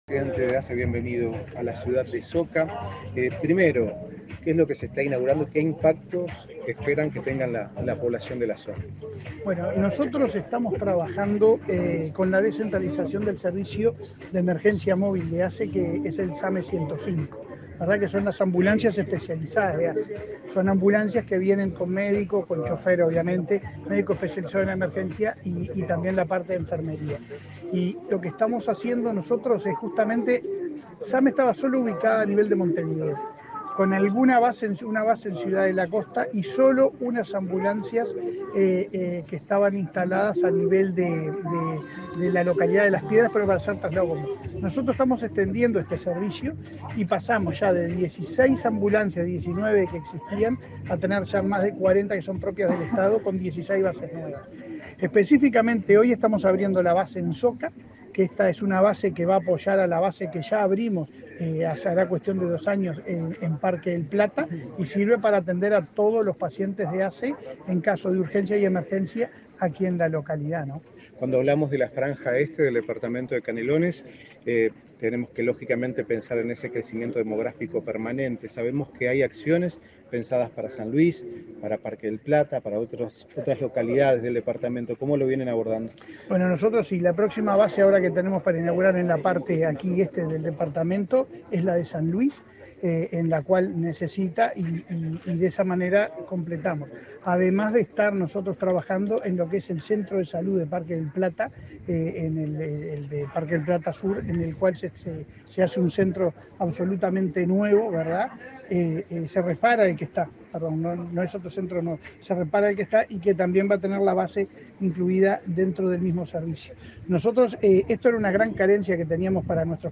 Declaraciones a la prensa del presidente de ASSE, Leonardo Cipriani
Declaraciones a la prensa del presidente de ASSE, Leonardo Cipriani 11/12/2023 Compartir Facebook X Copiar enlace WhatsApp LinkedIn Tras participar en la inauguración de la base del servicio de emergencia SAME 105 en la localidad de Soca, este 11 de diciembre, el presidente de la Administración de Servicios de Salud del Estado (ASSE), Leonardo Cipriani, realizó declaraciones a la prensa.